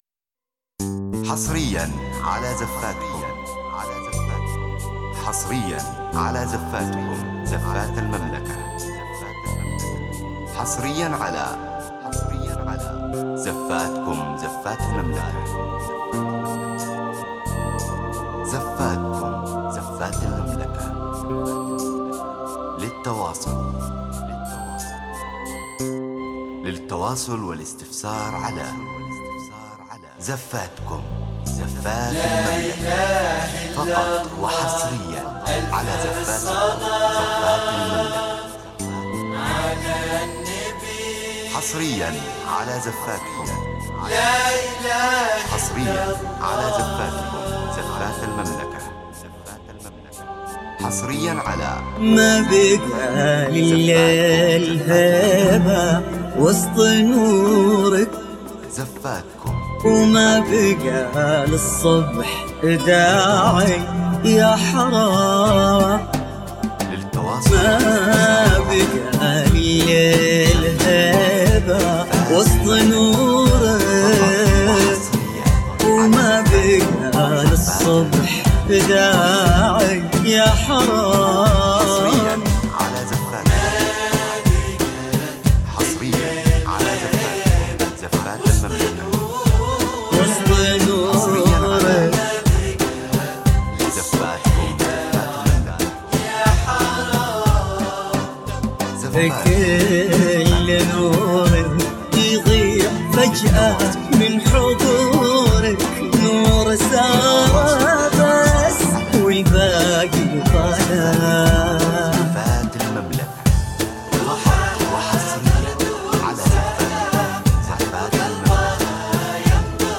بآهات ودفوف نقية
زفات إسلامية